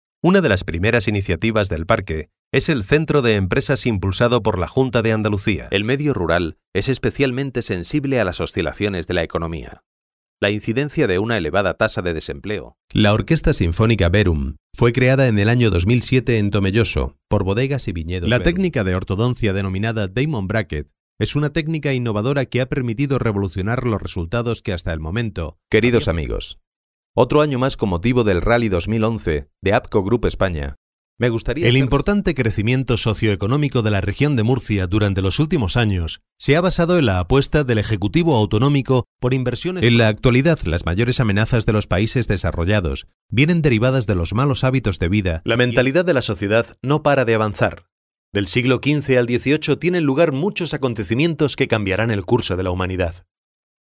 Adulto joven, adulto +40, español neutro, español andaluz, Publicidad, Documental, E-learning, Dibujos animados, Jingles, Canciones, Doblaje, Juegos, Presentaciones, Podcasts/internet, Sistema de teléfono, Moderación (on), Audiolibros, estudio propio.
Sprechprobe: Industrie (Muttersprache):
Type of voice: Spanish. Tenor, young adult, Adult +40.